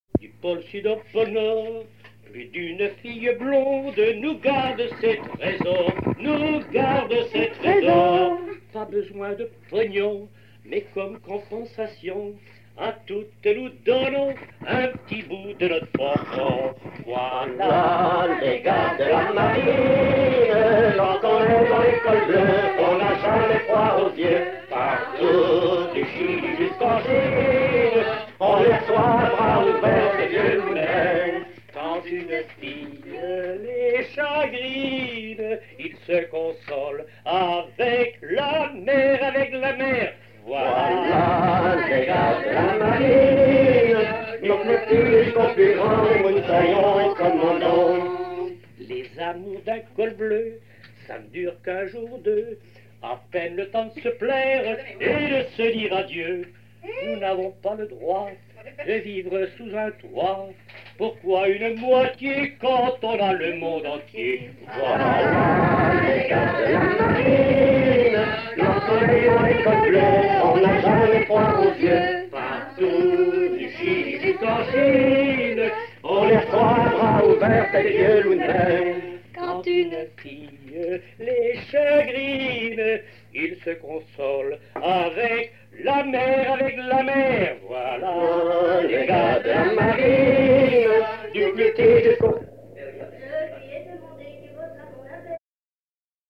Localisation Île-d'Yeu (L')
Genre strophique
collectage
Pièce musicale inédite